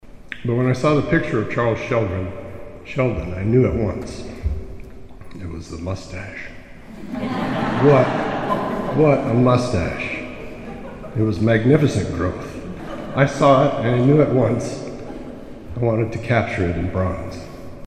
Bronze statues of South Dakota Govs. Charles Sheldon (2), Coe Crawford (6) and Carl Gunderson (11) were unveiled at the State Capitol in Pierre this morning (June 11).